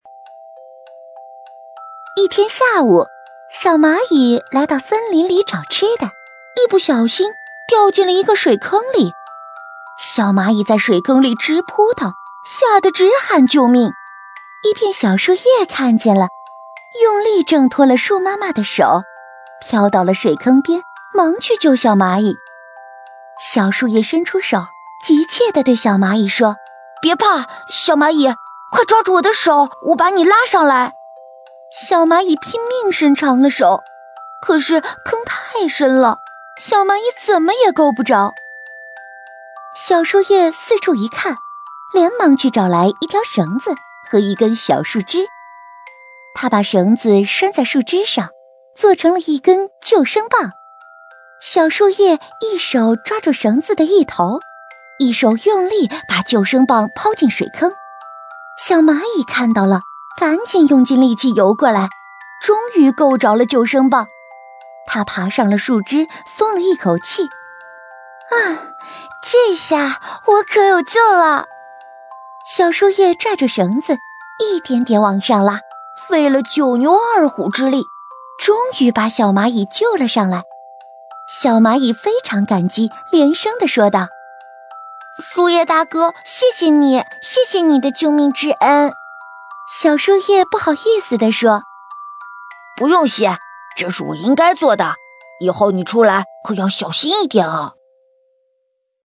129女声样音试听
129女声儿童故事小蚂蚁
129女声儿童故事小蚂蚁.mp3